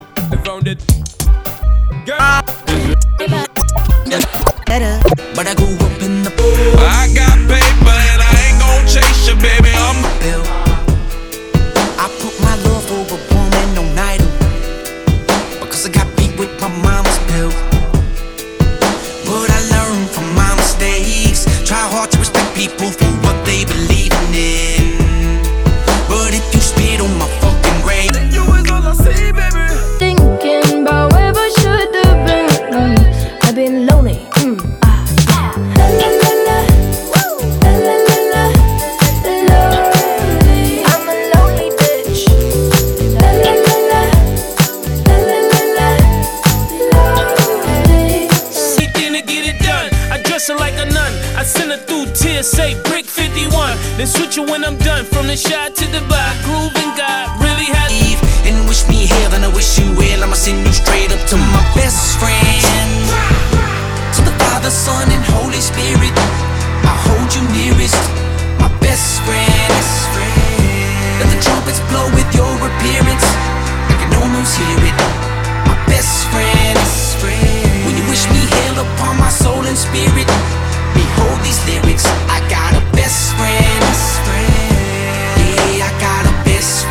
Indie